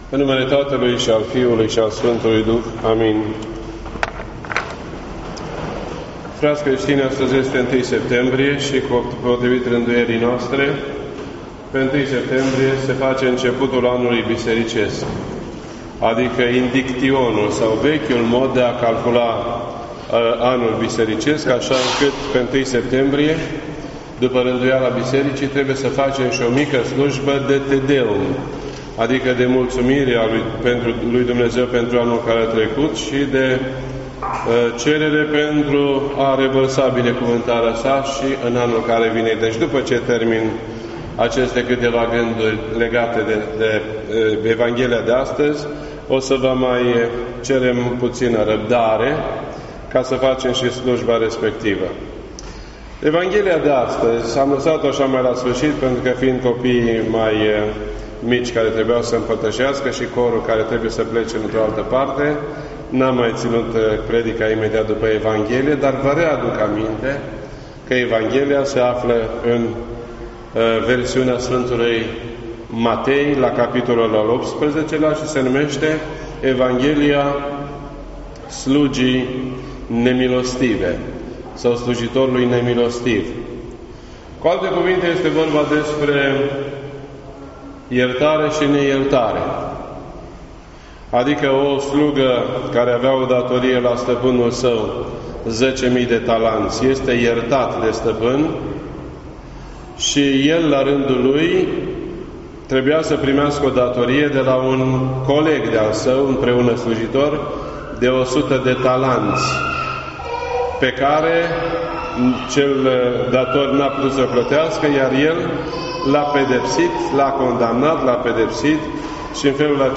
This entry was posted on Sunday, September 1st, 2019 at 7:54 PM and is filed under Predici ortodoxe in format audio.